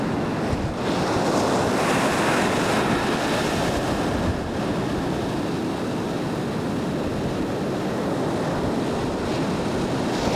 I caught Zuma beach with almost no wind once and whipped out my portable recorder.
In an earlier part of the clip I think I can detect that weird, wine-glass cellphone sound of it trying to suppress all that water “noise.”
The water is the show.